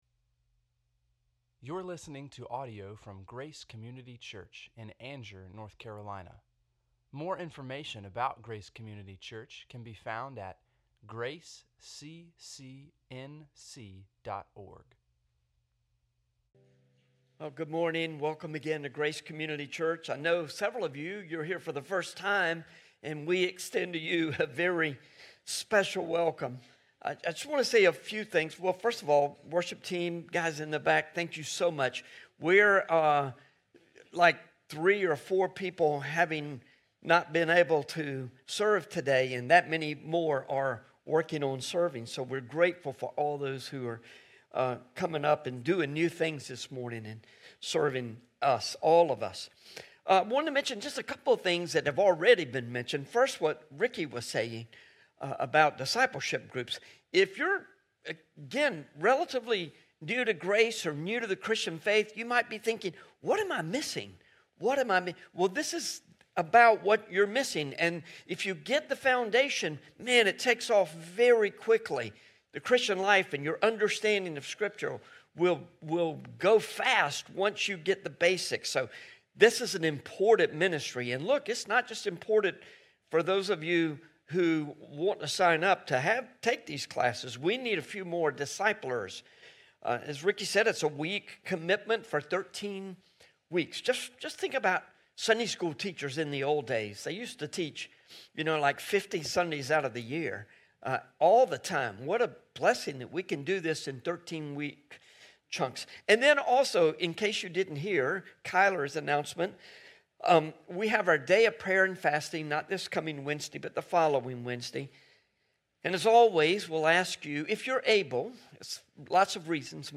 7-27-25-sermon.mp3